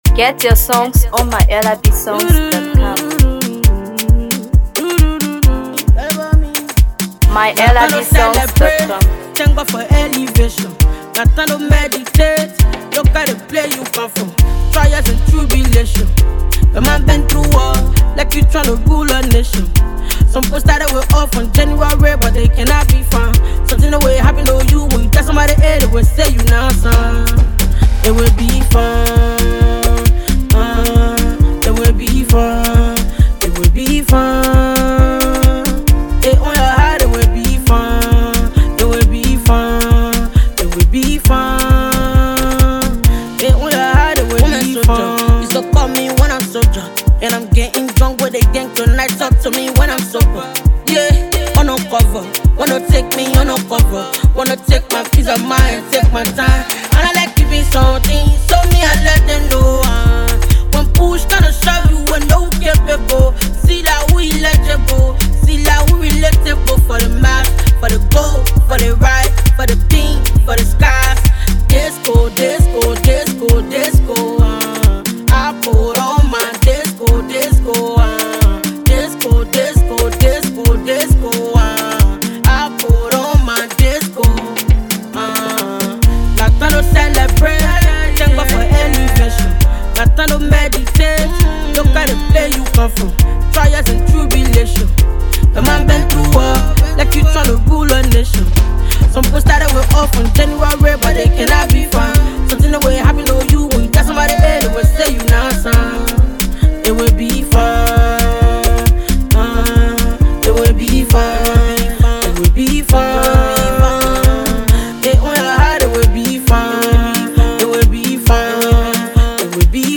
Afro PopHipcoMusic
Liberia’s top-notch female Afrobeats and Hipco rapper
Fusing infectious Afrobeats vibes with her fierce Hipco flow
a catchy hook